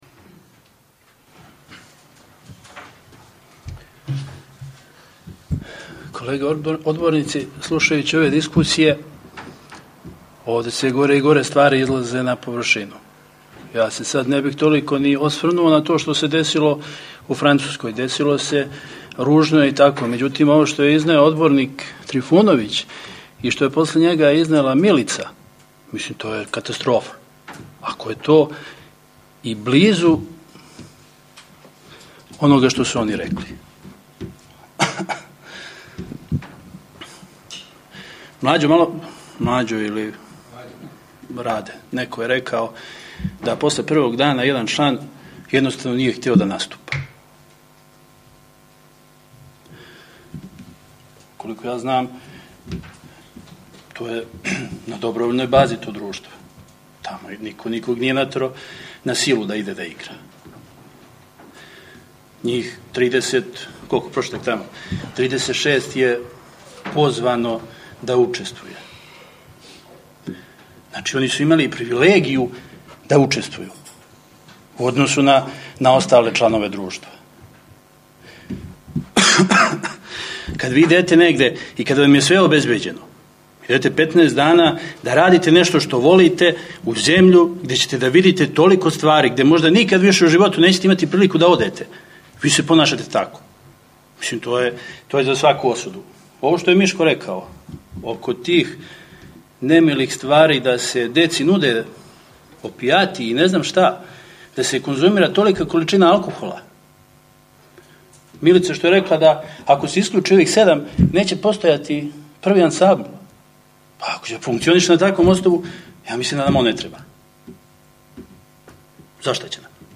3. СЕДНИЦУ СКУПШТИНЕ ОПШТИНЕ КОСЈЕРИЋ